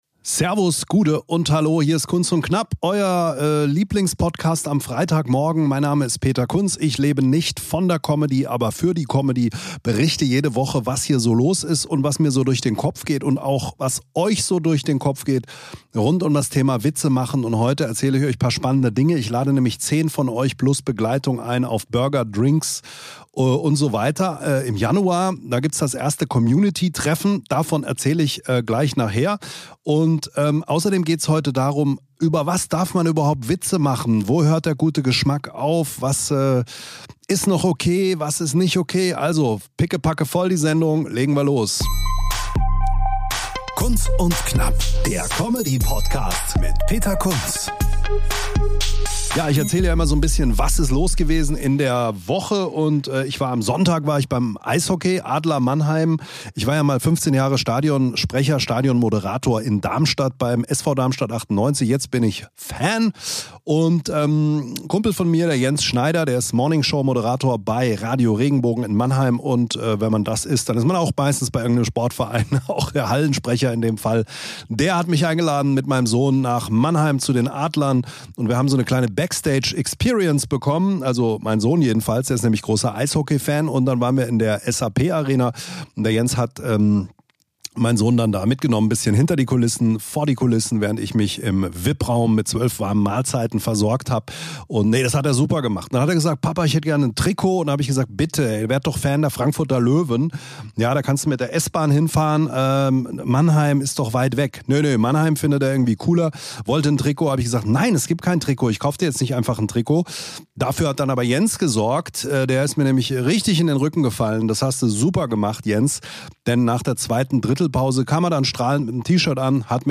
Komödie
Stand-up Comedy